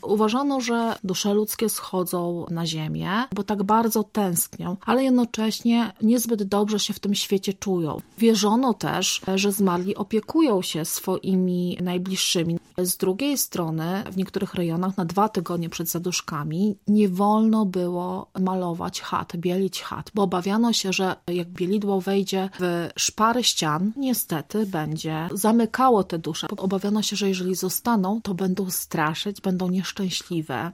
Dusze zmarłych odwiedzają miejsca znane za życia. Etnograf o Zaduszkach